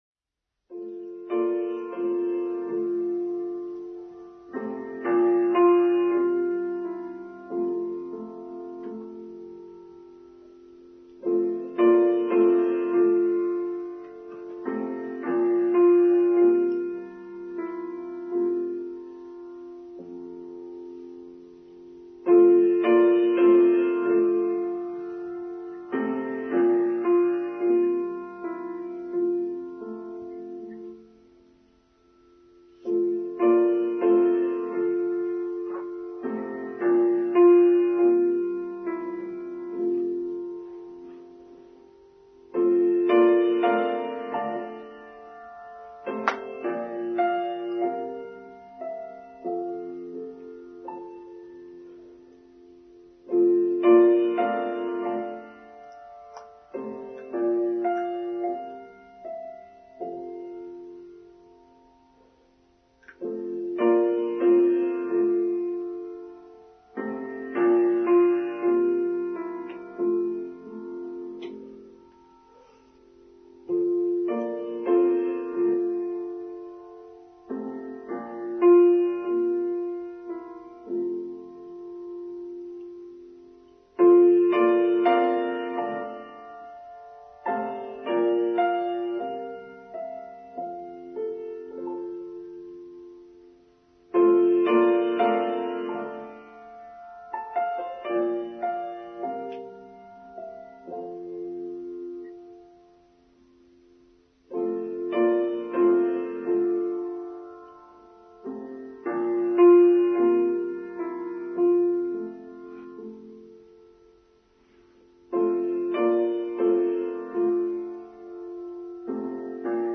Scott of the Antarctic: Online Service for Sunday 13th March 2022